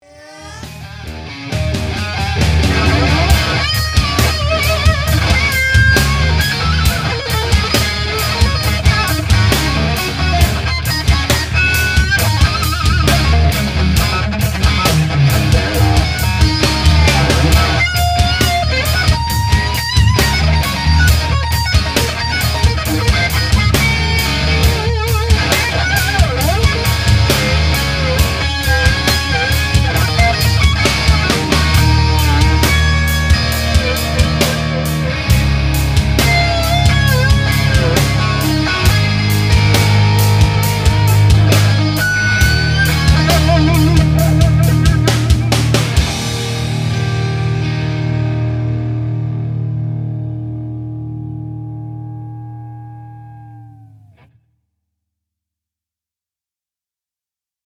Equipment used audio clip #1: Fender Reissue Strat, 1971 Marshall SuperLead 100 watt (Model: 1959) amp, PRX150-DAG attenuator ("E" Step Attenuation level), Marshall 4x12 cabinet equipped with Celestion G12H Heritage 30 watt speakers (55Hz) cones. Guitar directly into amp, no effects. SM57 mic into Apple Logic.